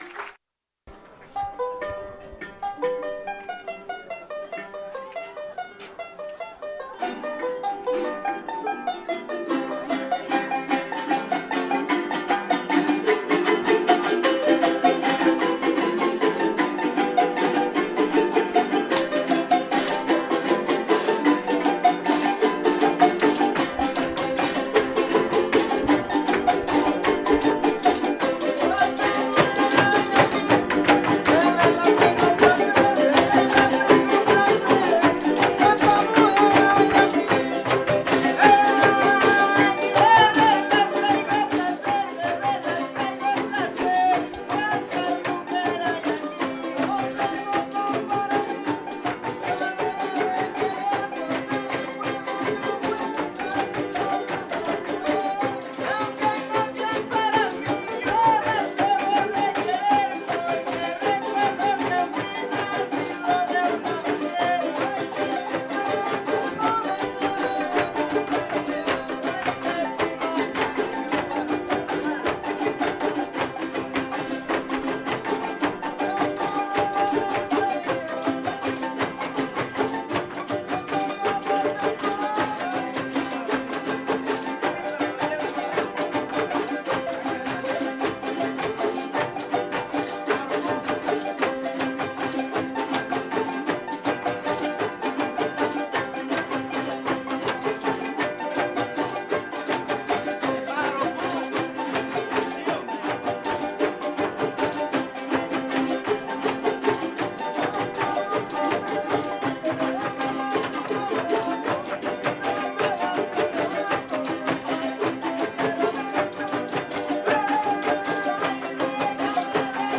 Canción tradicional Son jarocho
Tres Zapotes, Veracruz, Mexico
Fiesta Patronal de San Antonio de Padua